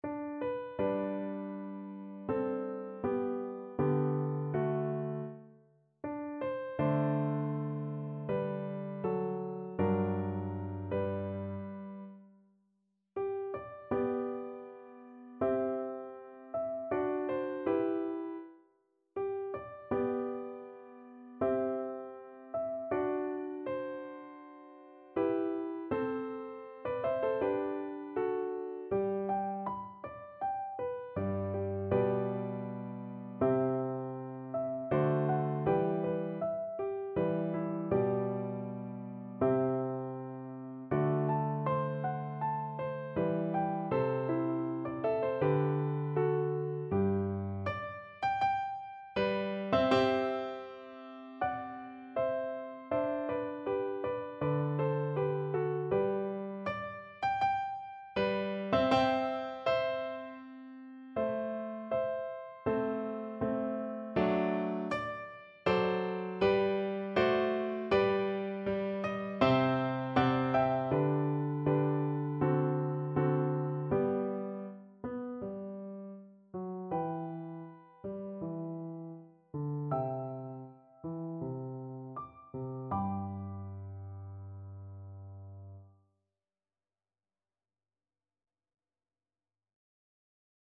Piano version
Key: G major
Time Signature: 4/4
Tempo Marking: Andante
Instrument: Piano
Style: Classical